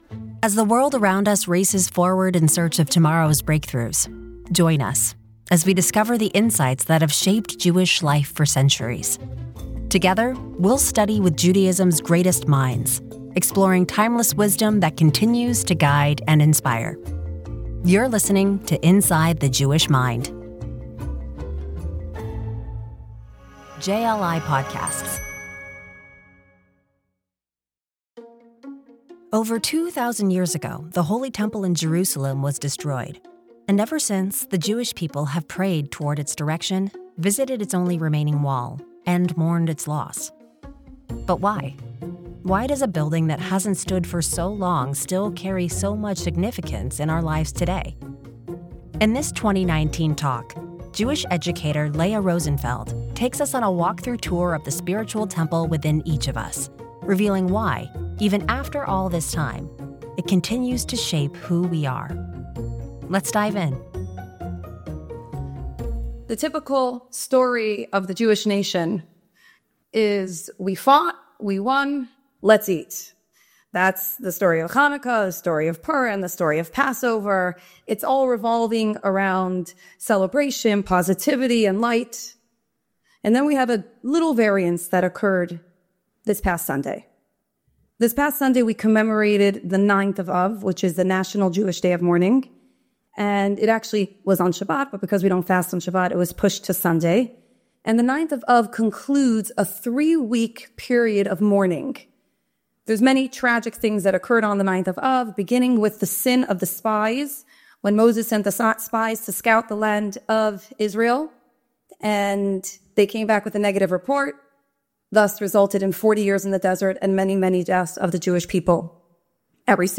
Two thousand years later, the Jewish people still mourn the destruction of the Holy Temple that once stood in Jerusalem. But why does a long-lost building continue to hold such a powerful place in our collective heart? In this 2018 talk